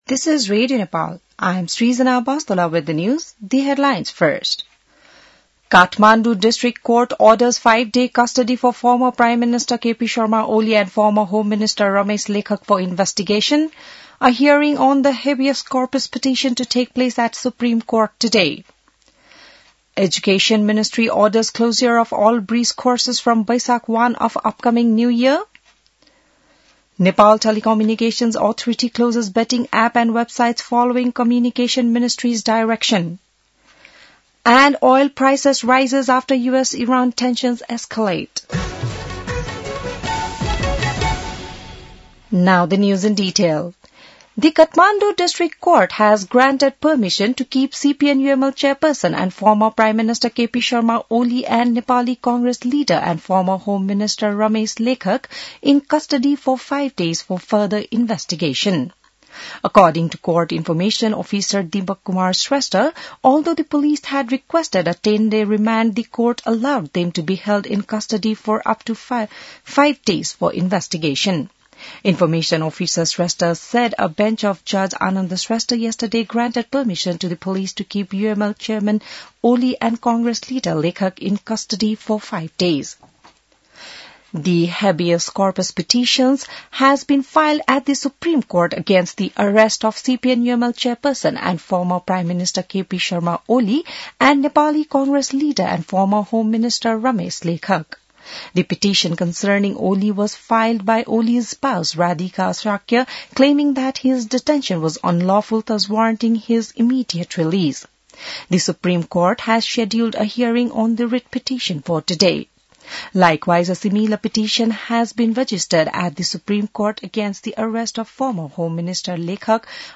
बिहान ८ बजेको अङ्ग्रेजी समाचार : १६ चैत , २०८२